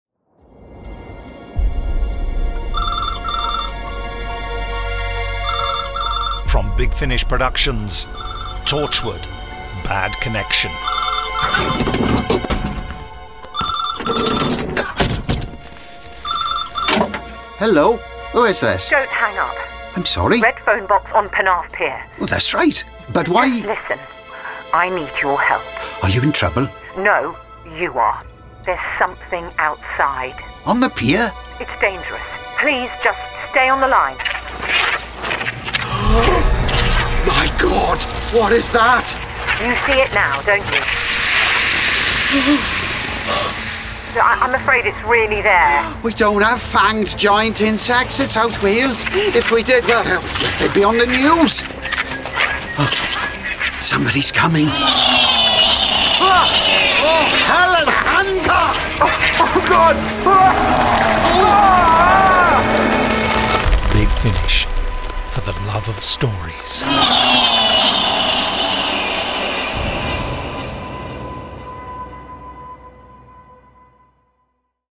Torchwood: Bad Connection Released November 2024 Written by Aaron Lamont Starring Indira Varma Jason Watkins This release contains adult material and may not be suitable for younger listeners. From US $12.66 CD + Download US $15.19 Buy Download US $12.66 Buy Save money with a bundle Login to wishlist 5 Listeners recommend this Share Tweet Listen to the trailer Download the trailer